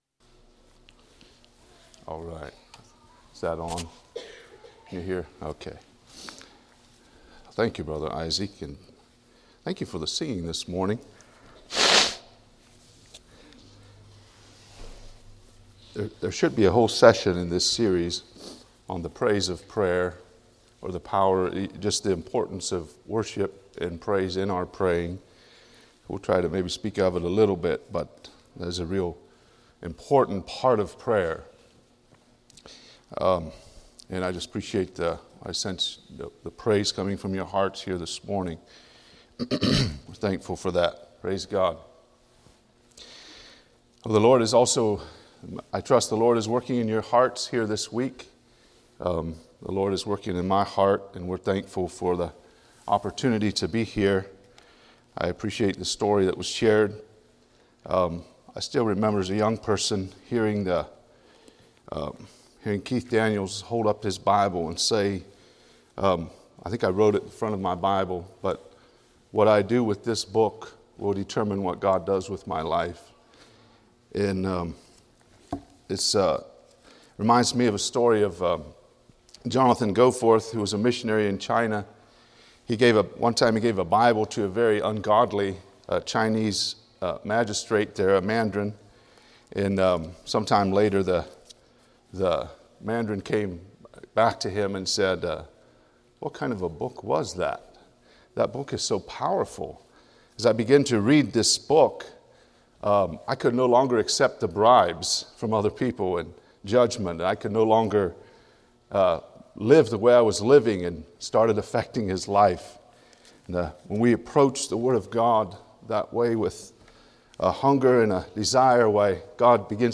A message from the series "Bible Boot Camp 2024."